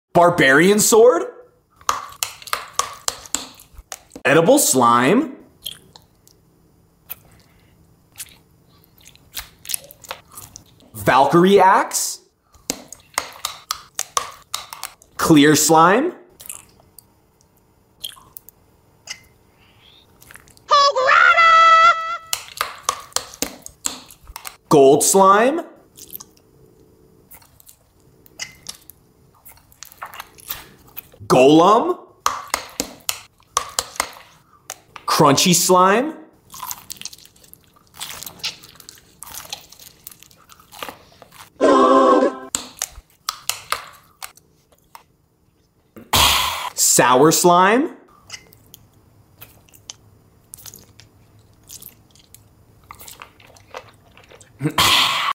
Edible Slime ASMR!?